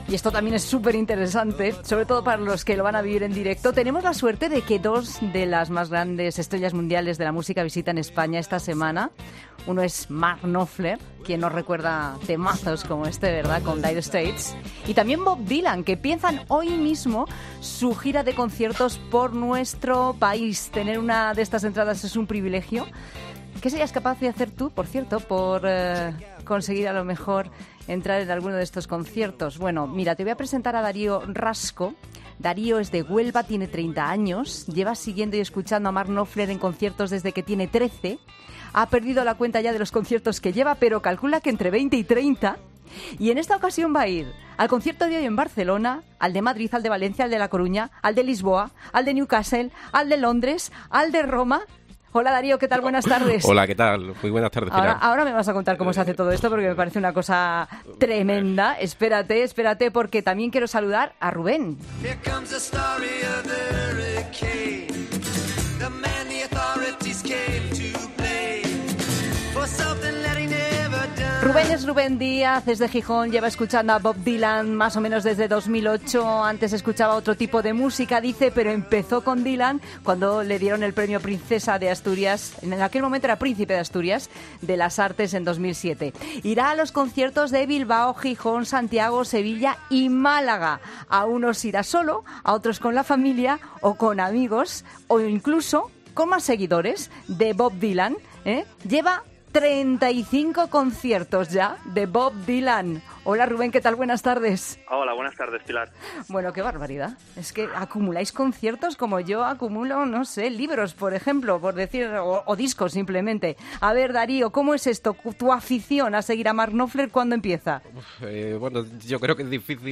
Los dos cantantes arrancan hoy su gira en España. En La Tarde hablamos con dos fans que no se perderán un concierto